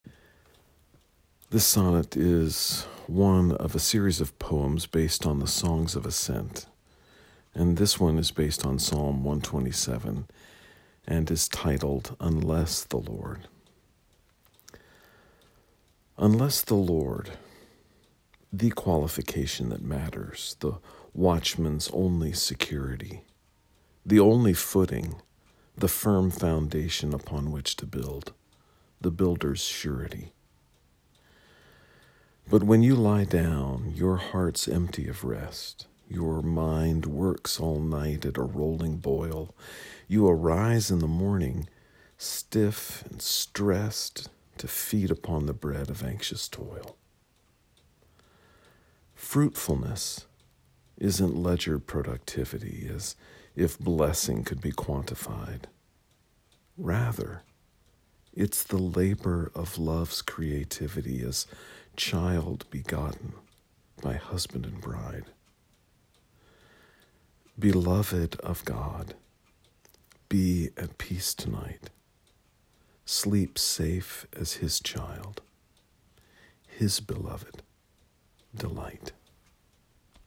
You may listen to me read the sonnet via the player below.